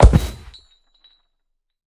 Minecraft Version Minecraft Version snapshot Latest Release | Latest Snapshot snapshot / assets / minecraft / sounds / mob / ravager / step5.ogg Compare With Compare With Latest Release | Latest Snapshot
step5.ogg